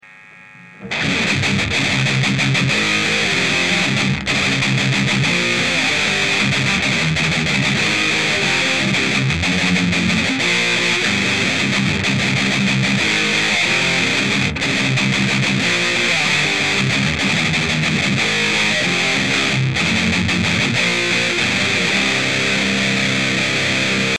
����� SVS TD III Tube Distortion
��� ������ � ��������, VOL=12 TREBLE=12 MIDDLE=10 BASS=9 GAIN=15 �����[attach=1][attach=2]